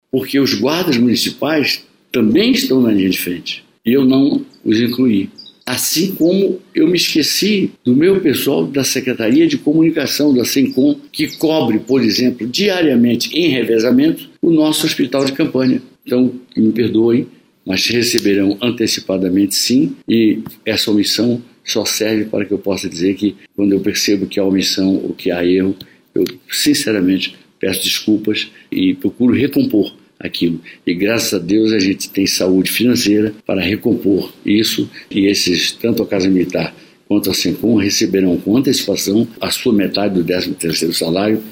O prefeito disse que graças à saúde financeira que alcançaram, acrescentará mais essas duas categorias essenciais na antecipação do décimo.